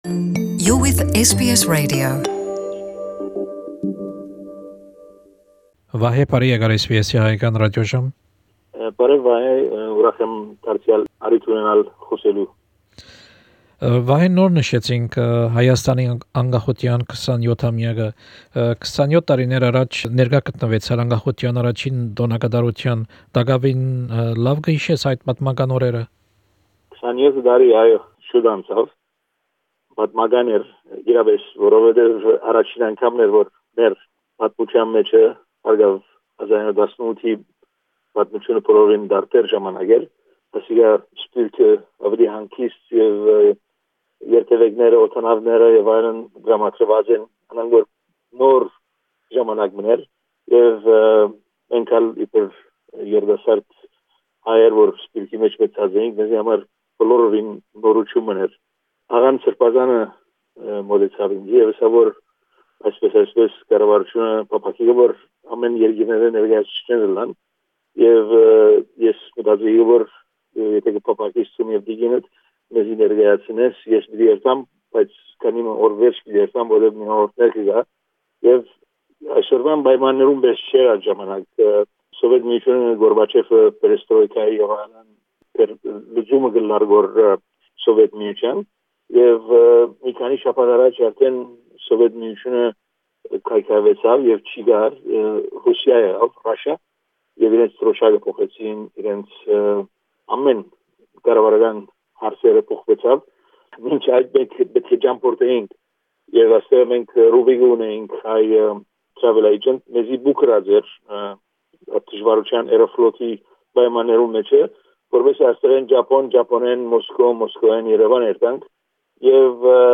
He recounts the two incredible weeks he spent in Armenia (full interview).